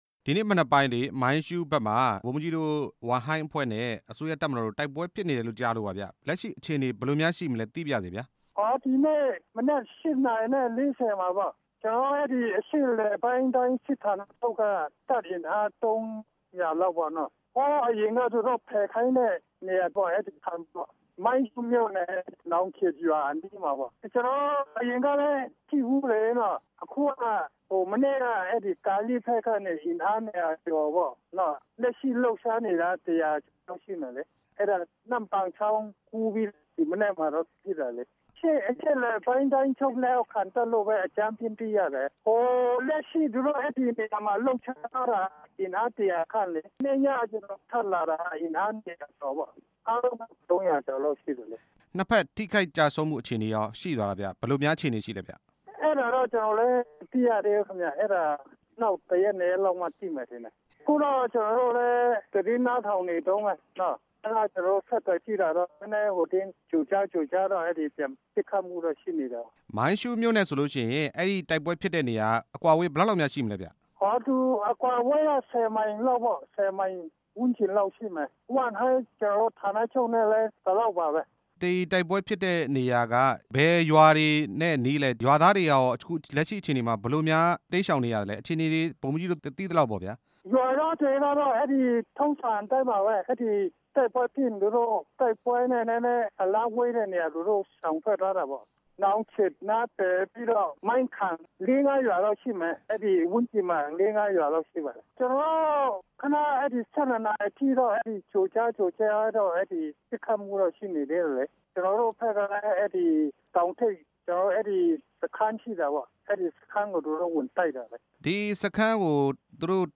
SSPP/SSA တပ်နဲ့ တိုက်ပွဲဖြစ်ပွား မှုအကြောင်း မေးမြန်းချက်